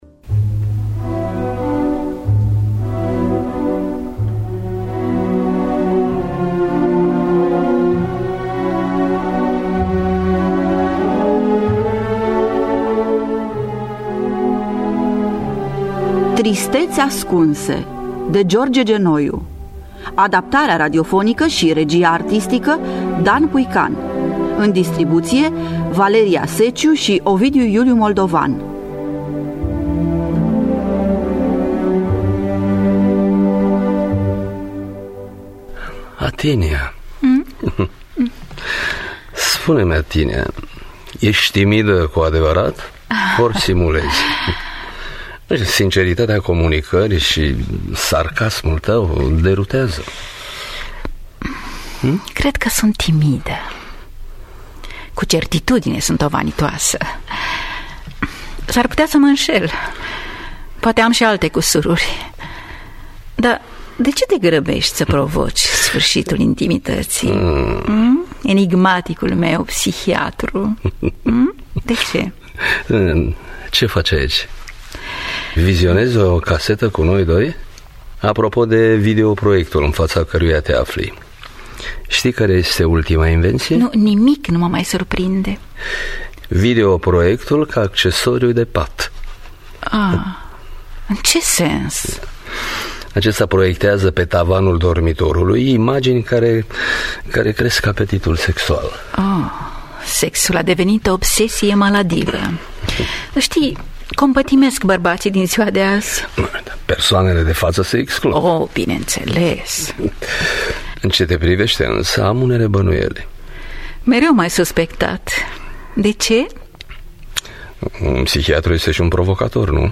Adaptarea radiofonică
În distribuţie: Valeria Seciu şi Ovidiu Iuliu Moldovan.